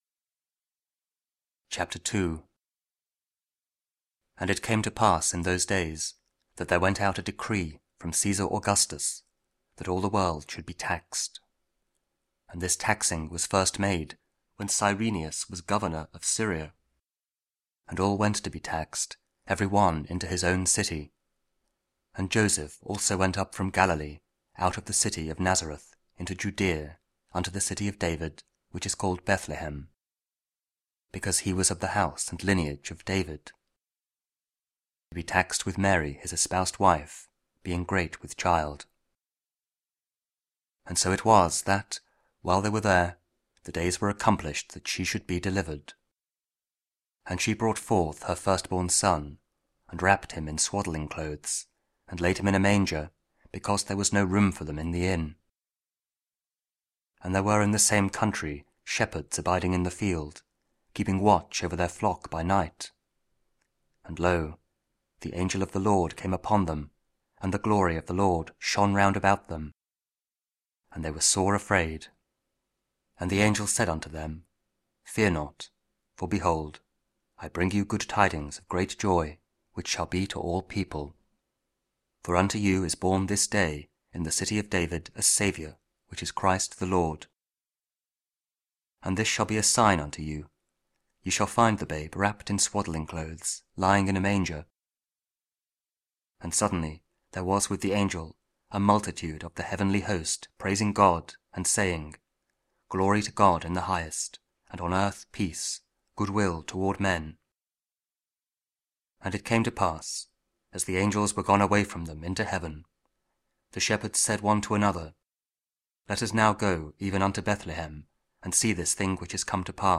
Luke 2: 16-21 – Solemnity of Mary, the Mother of God (Audio Bible, Spoken Word)